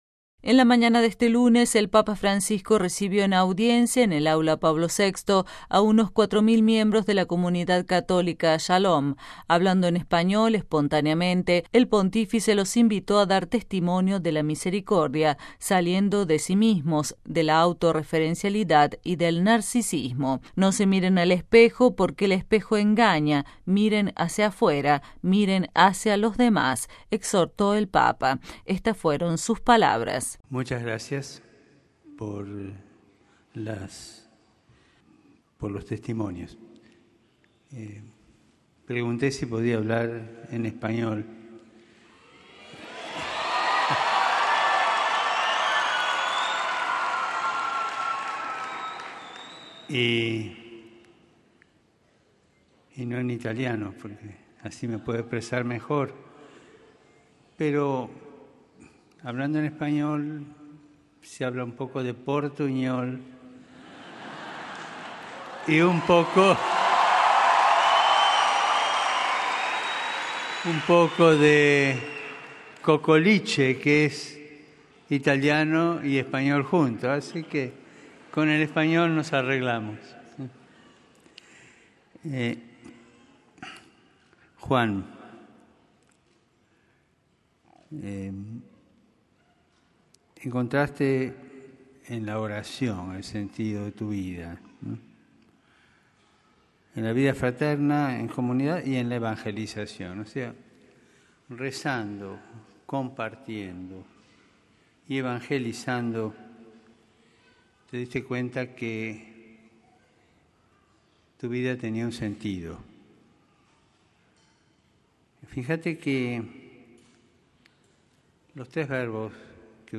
En la mañana de este lunes 4 de septiembre, el Papa Francisco recibió en audiencia, en el Aula Pablo VI, a unos 4.000 miembros de la Comunidad Católica Shalom. Hablando en español espontáneamente, el Pontífice los invitó a dar testimonio de la misericordia saliendo de sí mismos, de la autoreferencialidad y del narcisismo.